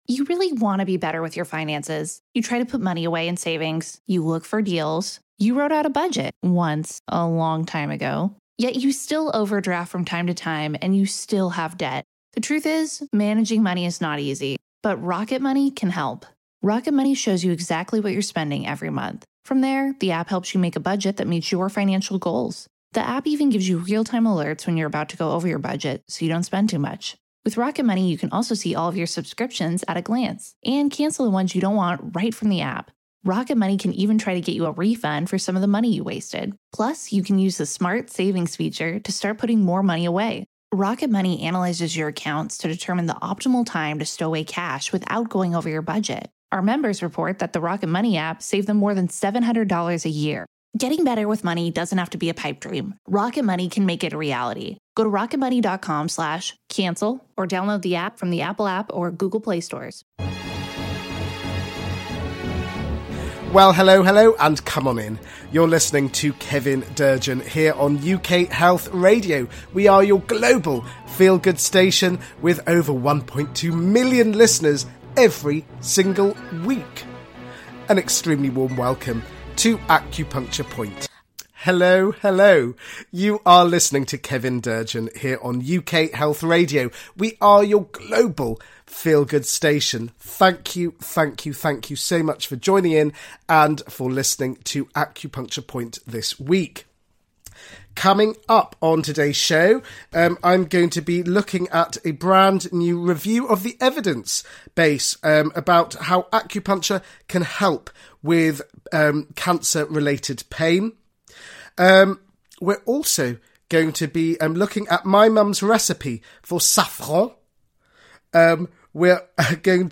He will also play some gorgeous music to uplift your soul and get your feet tapping with happiness.